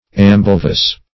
Search Result for " ambilevous" : The Collaborative International Dictionary of English v.0.48: Ambilevous \Am`bi*le"vous\, a. [L. ambo both + laevus left.]